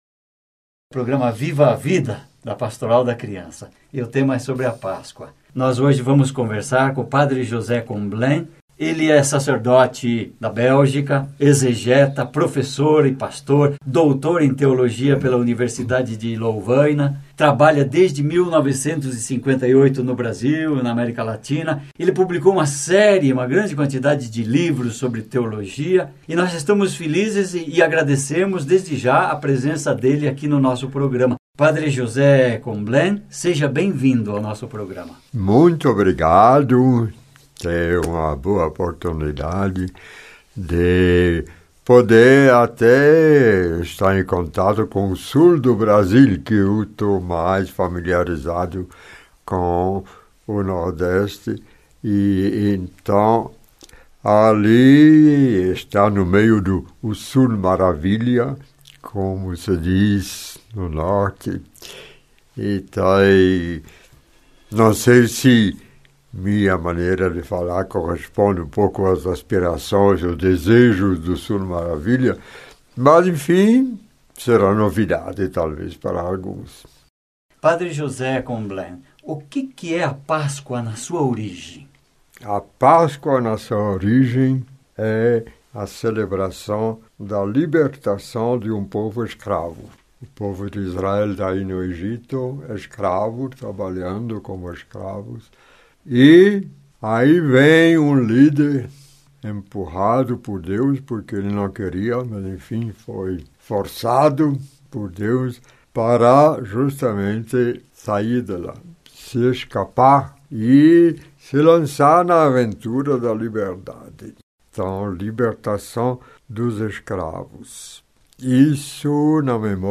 Entrevista com Padre Comblin - Pascoa - Pastoral da Crianca.mp3